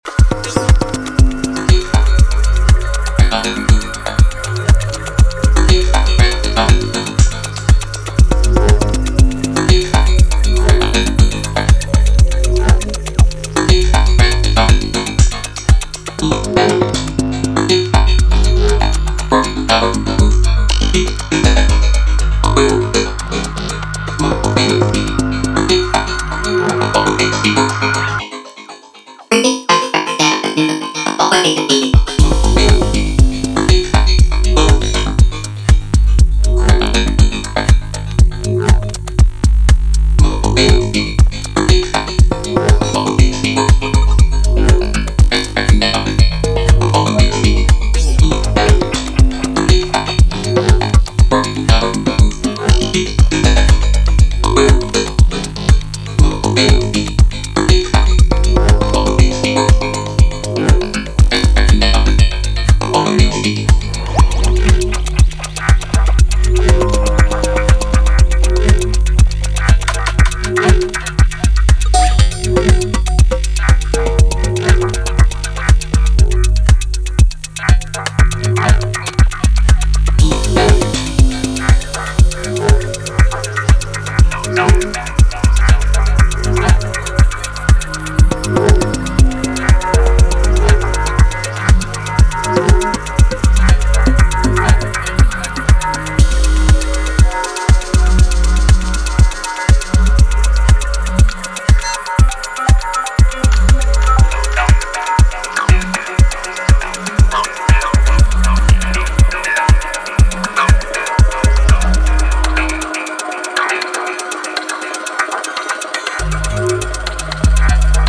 4 intricate signals for late-night movement.